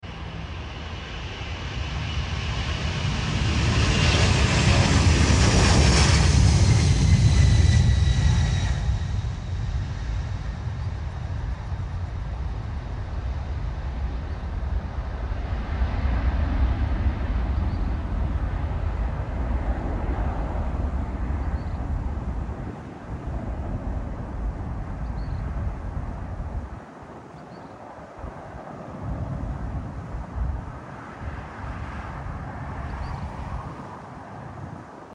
McDonnell Douglas MD-10-30F CP2791 TAB